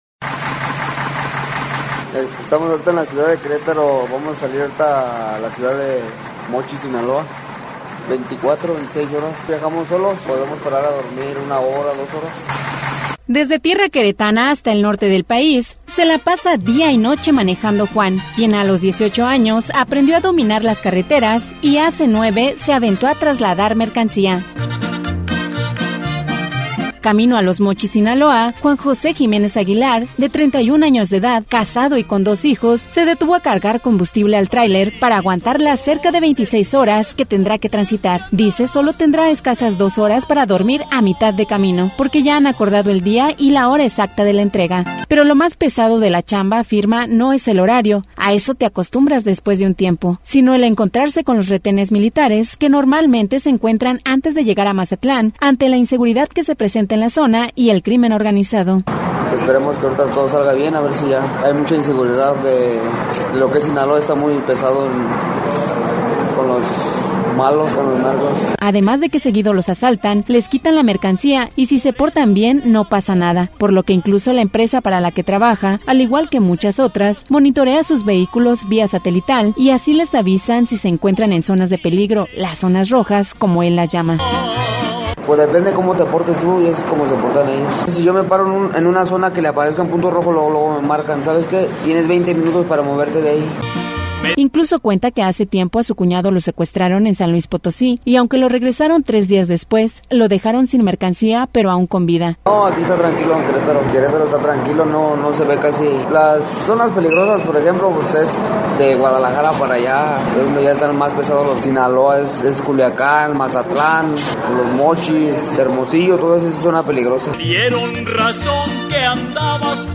Reportaje especial